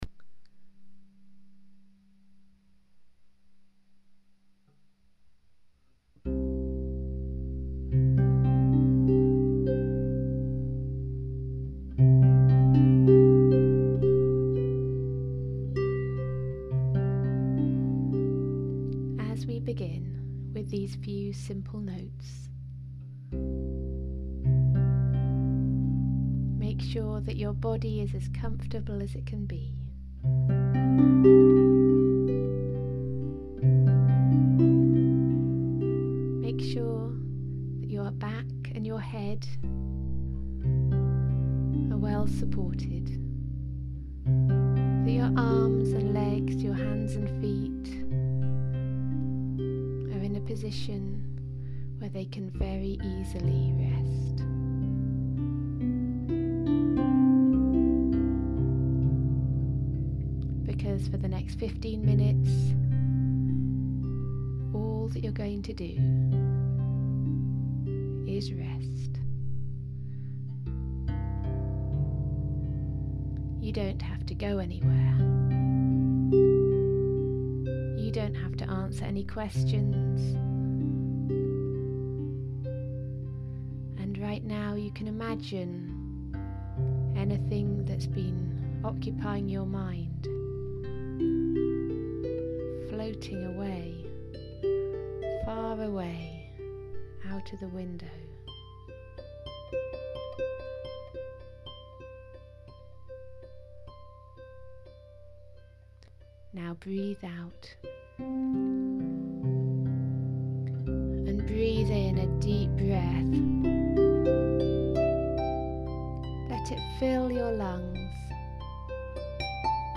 Originally played for World Meditation day, this MP3 is about 12 minutes of music for meditation or relaxation. It passes through 3 different modes, or moods, of music to help you travel through 3 stages of relaxation.
It begins in Suantraighe, which is soothing and sleepy, moves into Goltraighe, which is more reflective, going deeper, and finally ends in Geantraighe, which is positive and outreaching - the perfect state in which to end.
HARP FOR RELAXATION, MEDITATION & WELLBEING
Your+free+harp+relaxation+(12).mp3